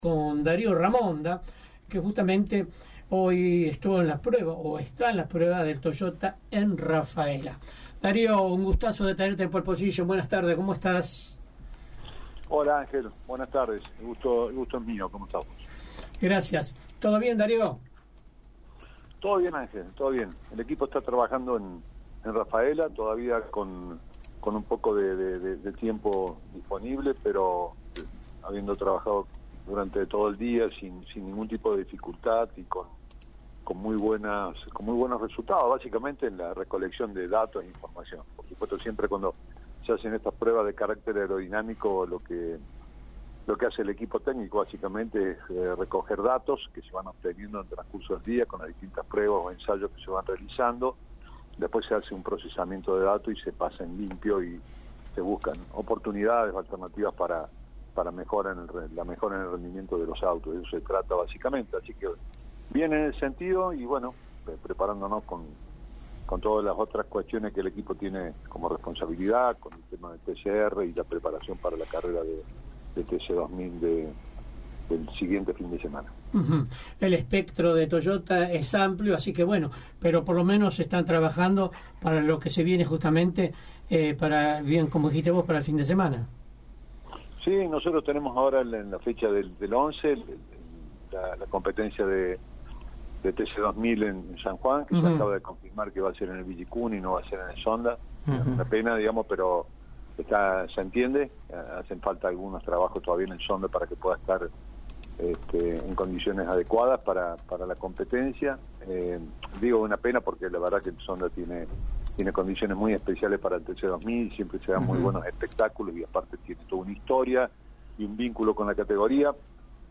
la nota